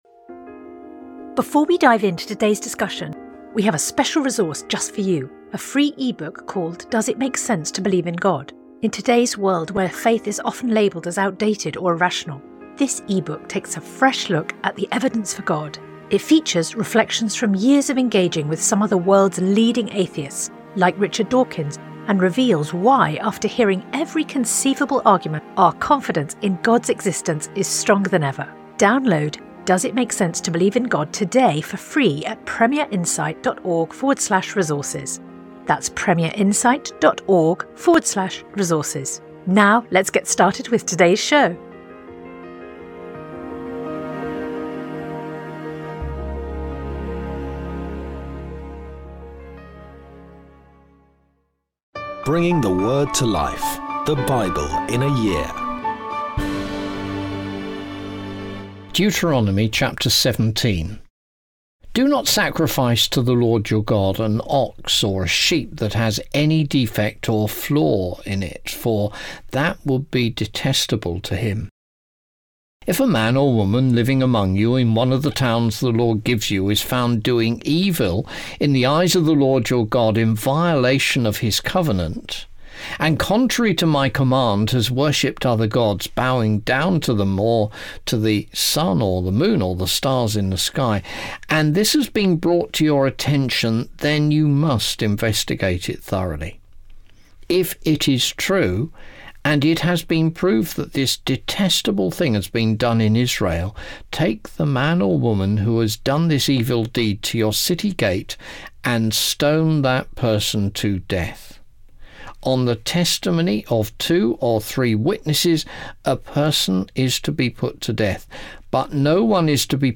Today's reading comes from Deuteronomy 17-18; Proverbs 7